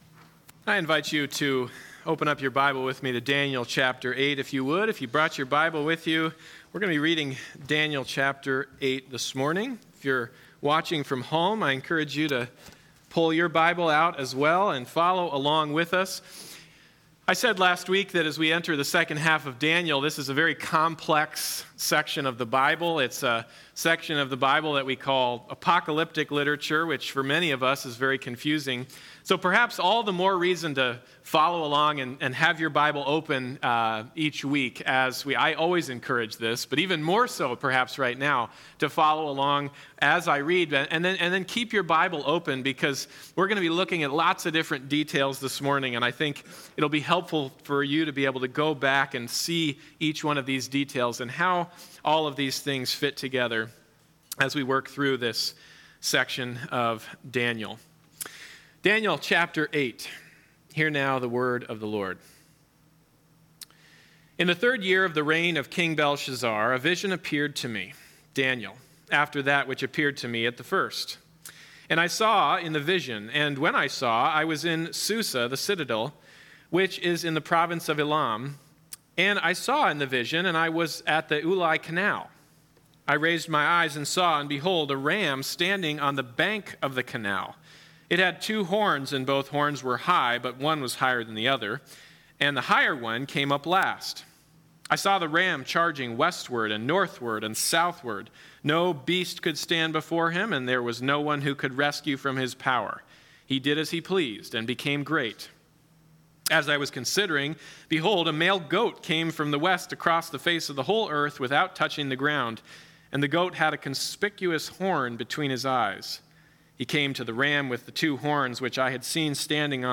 Bible Text: Daniel 8:1-27 | Preacher